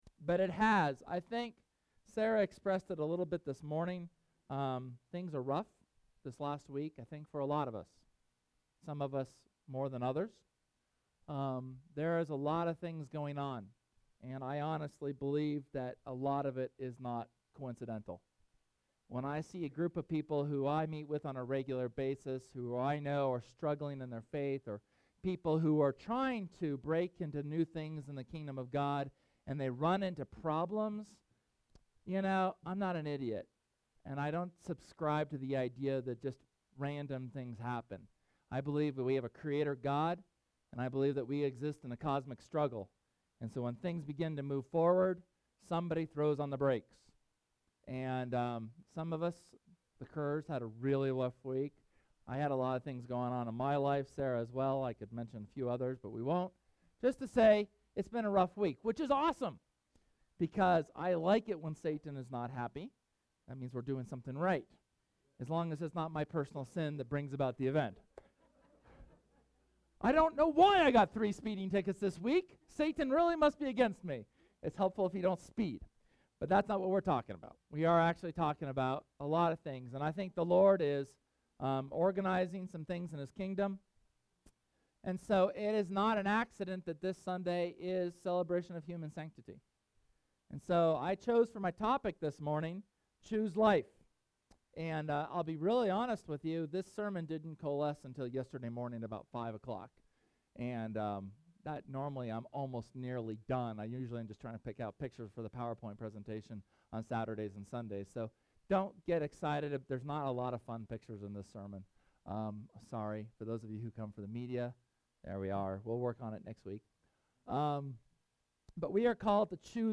Sermon from Sunday, January 20th on the importance of spiritual warfare and choosing life. At the end of the service we had a good time of intercession for the different spheres of influence within our church.